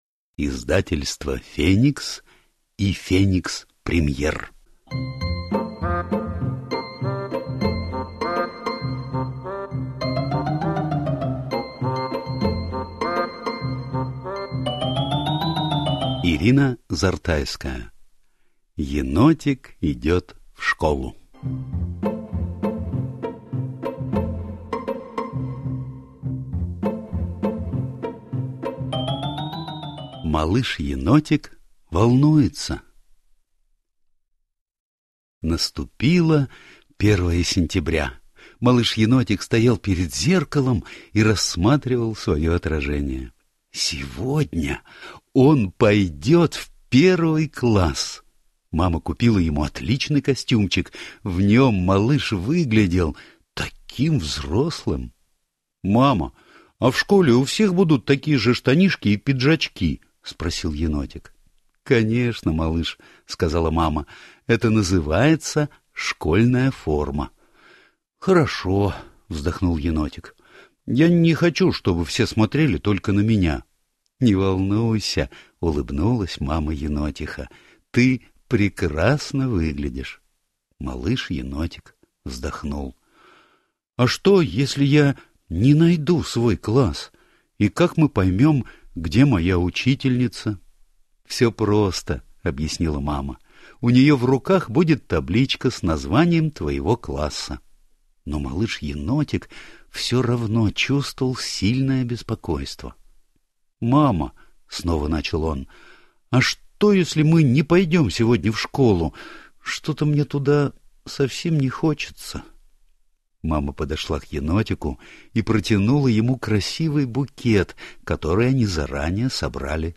Аудиокнига Енотик идет в школу | Библиотека аудиокниг